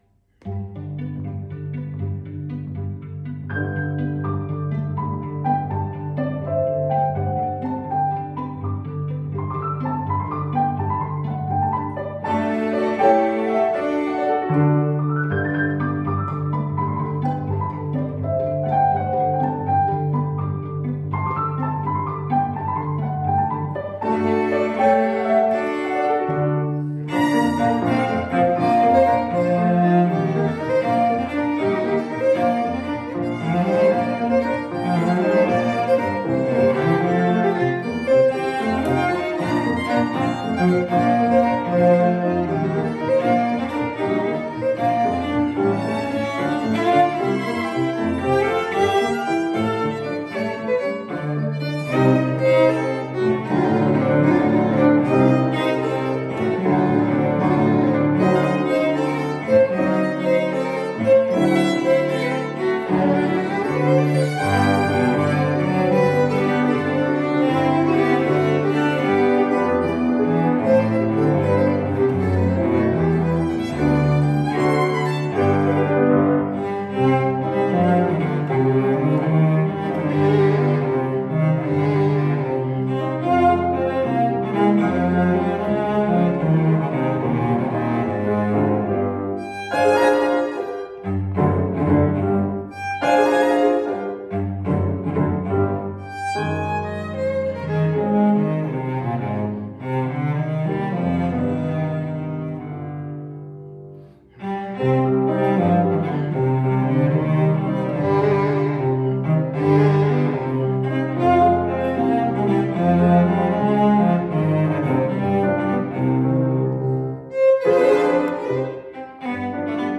The beautiful and evocative music speaks for itself.